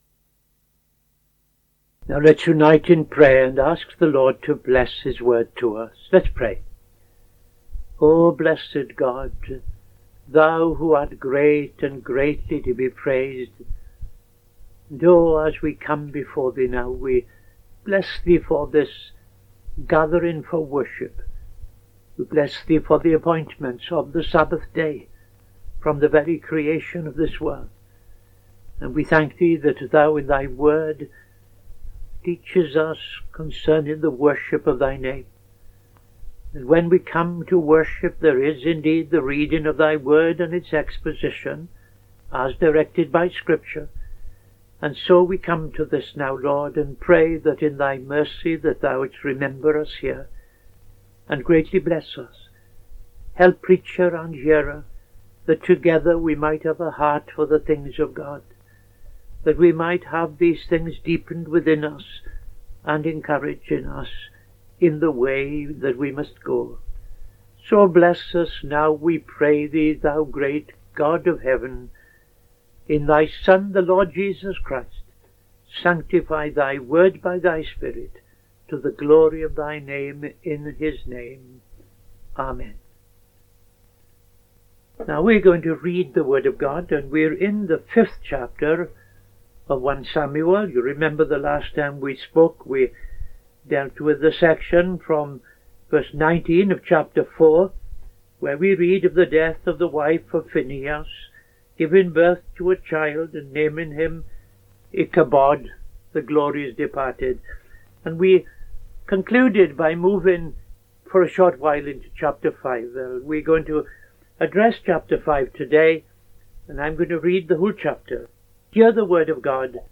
Bible Study - TFCChurch